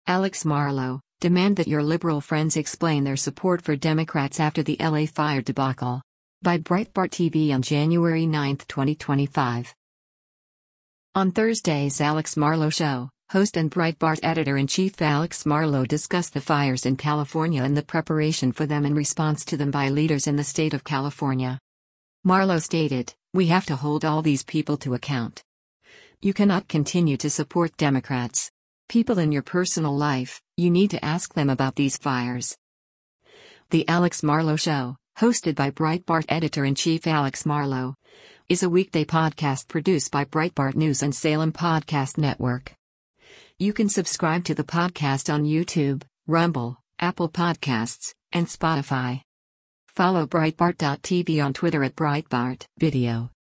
On Thursday’s “Alex Marlow Show,” host and Breitbart Editor-in-Chief Alex Marlow discussed the fires in California and the preparation for them and response to them by leaders in the state of California.